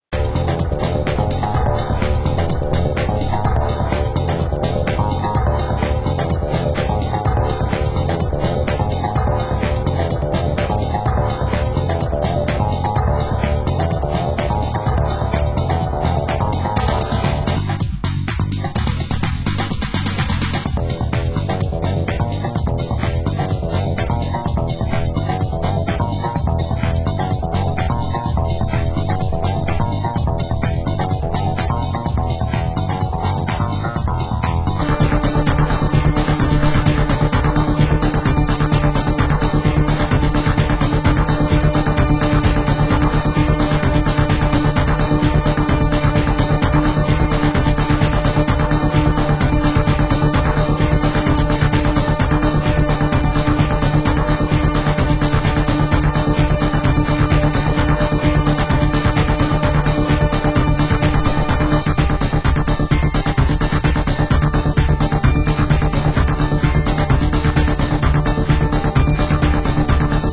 Genre: Euro House